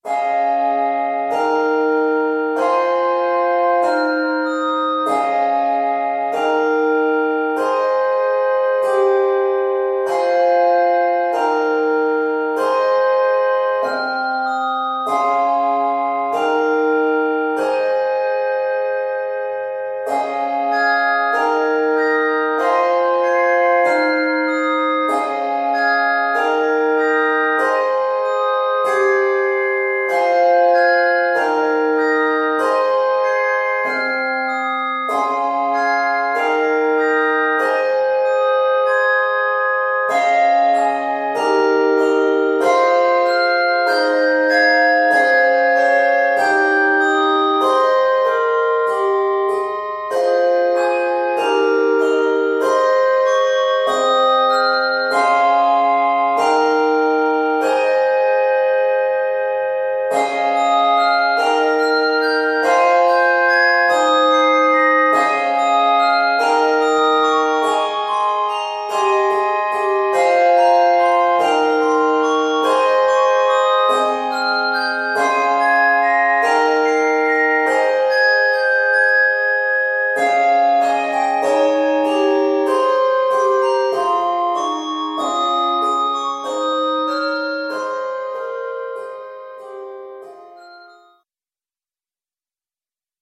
Scored in Ab Major, this piece is 72 measures.